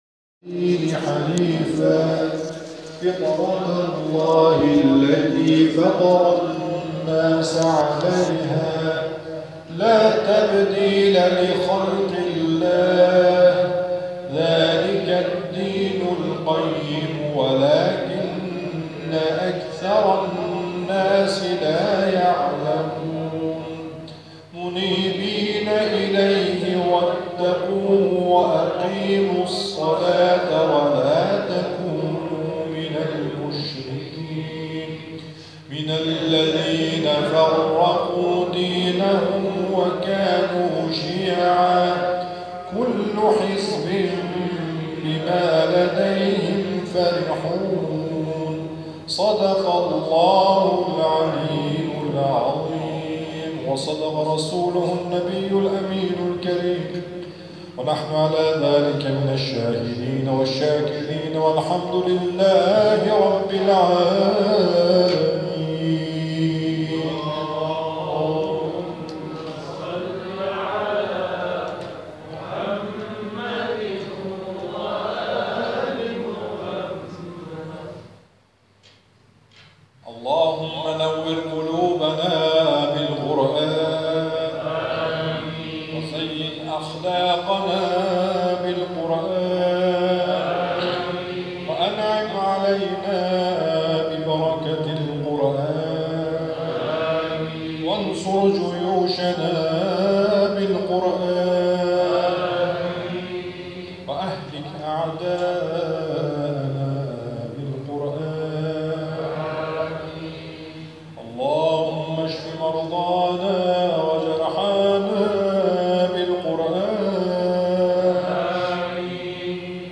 آخرین جلسه ترم تابستان
در ادامه جمع‌خوانی و دعای ختم قرآن